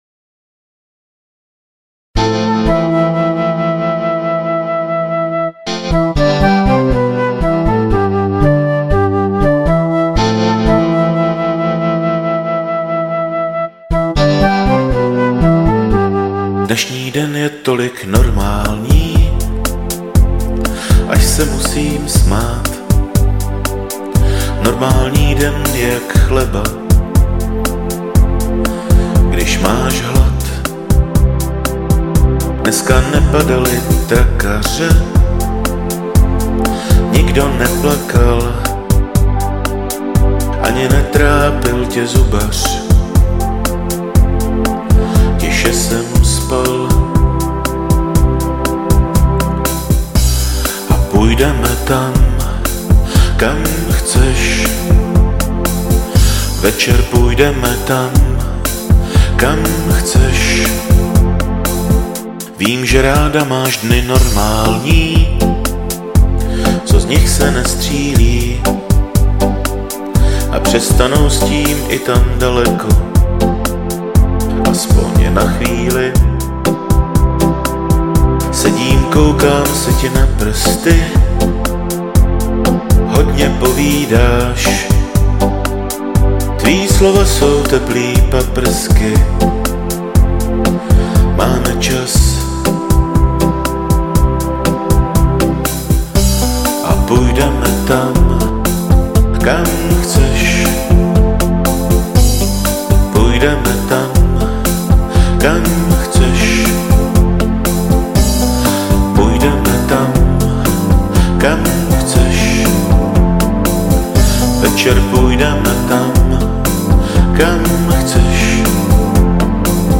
Žánr: Pop
písničkáře s notebokem.